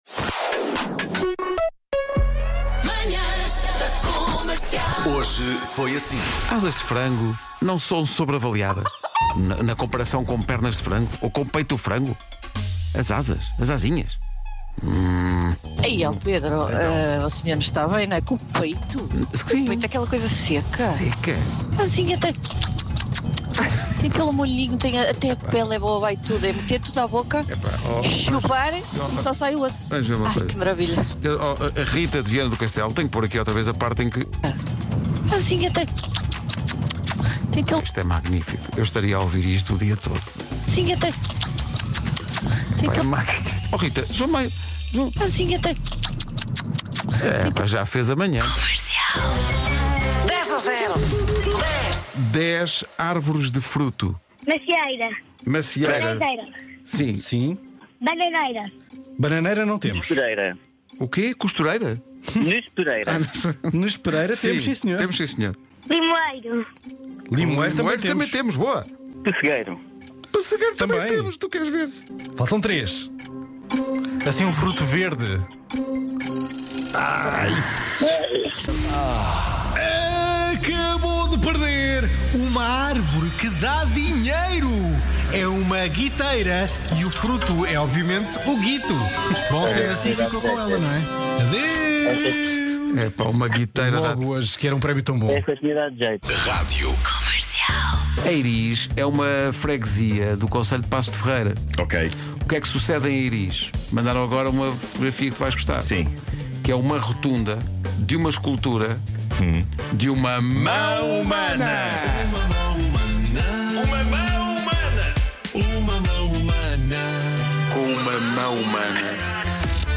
Os melhores momentos das Manhãs da Comercial, com Pedro Ribeiro, Nuno Markl, Vasco Palmeirim e Vera Fernandes.